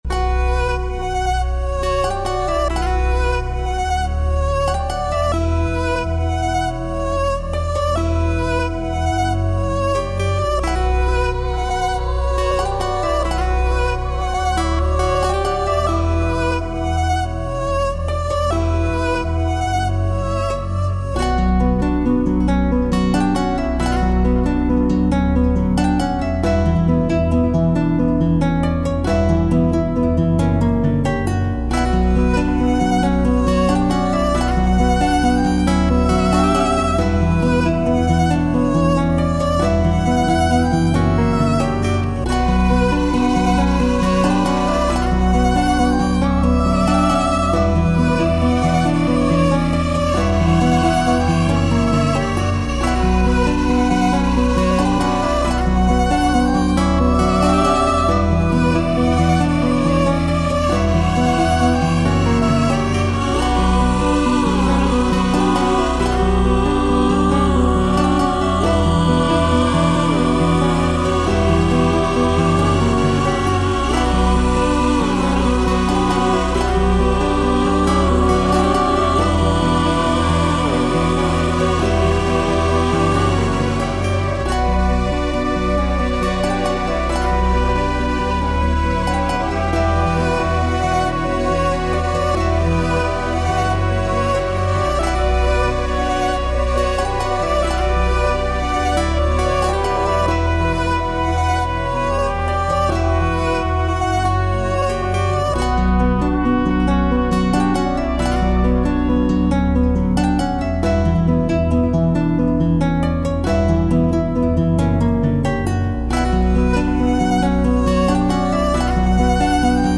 ひたすらに暗い雰囲気になるようにアコースティックギター、ストリングス、コーラスを用いて雰囲気を作っている。